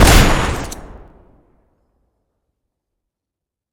shoot2.wav